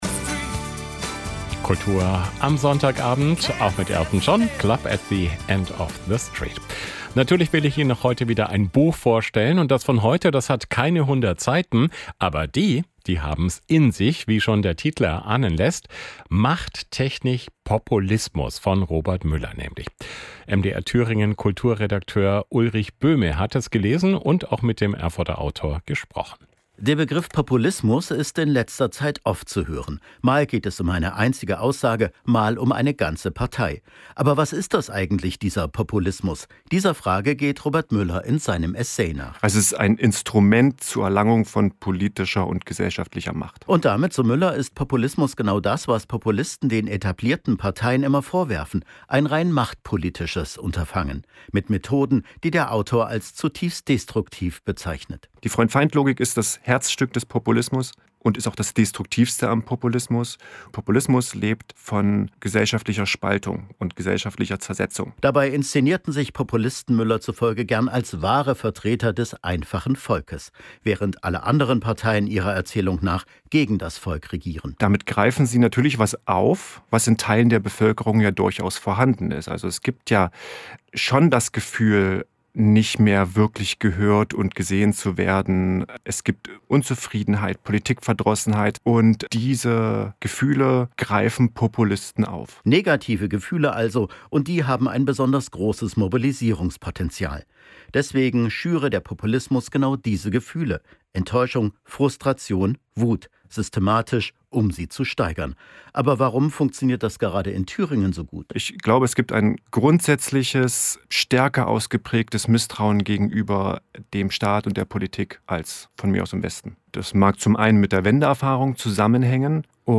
Radiofrei-Interview zu Machttechnik Populismus